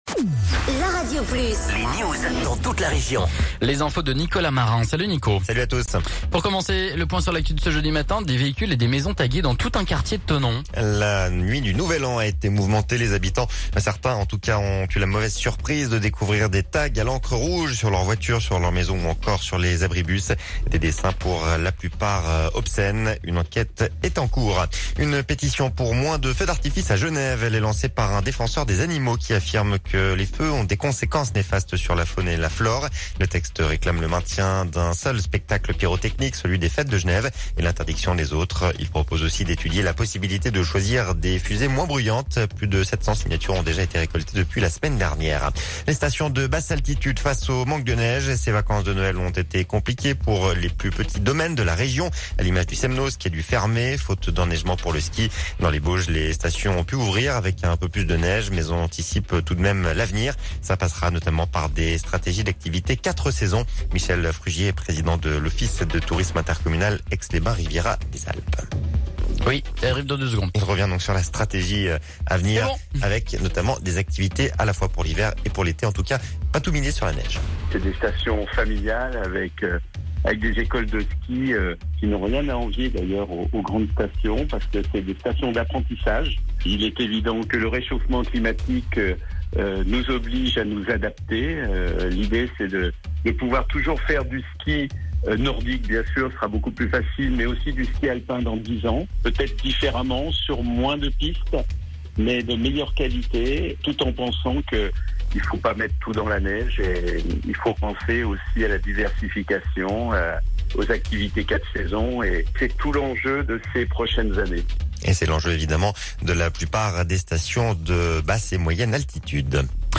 Reportage sur les Aigles du Léman à Sciez